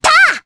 Rephy-Vox_Attack3_jpb.wav